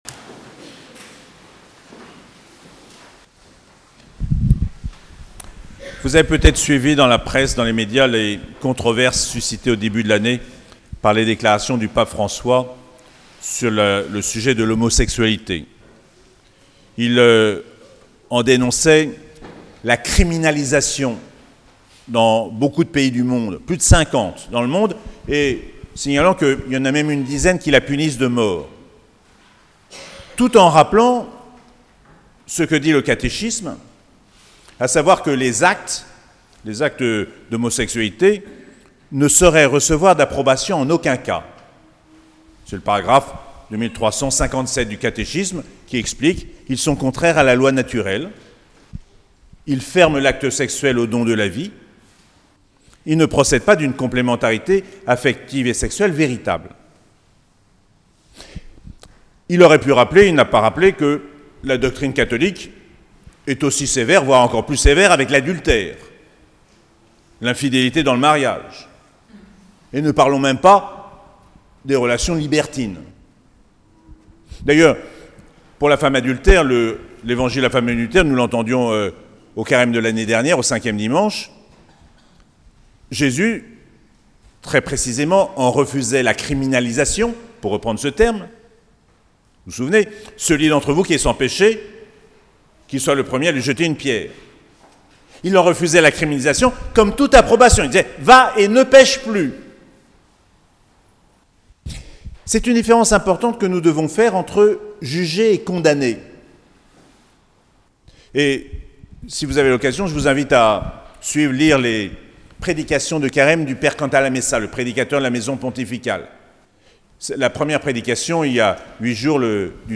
3ème dimanche de Carême - 12 mars 2023